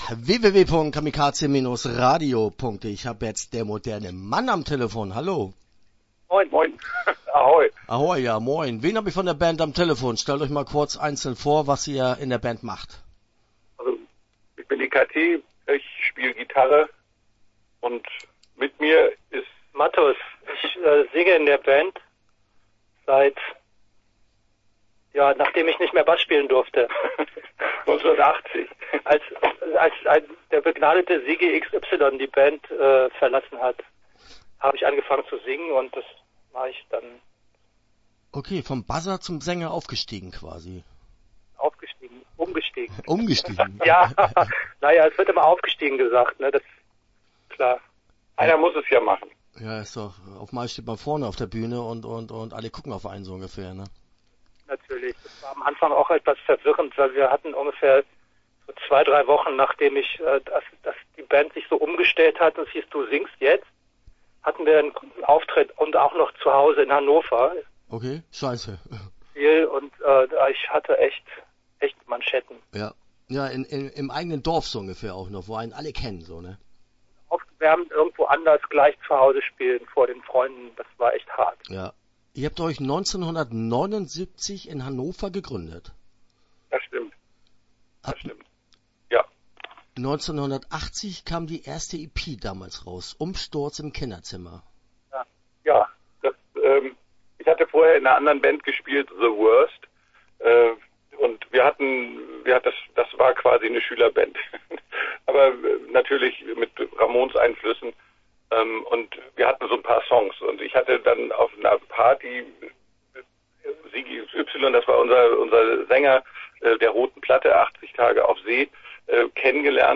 Der Moderne Man - Interview Teil 1 (11:14)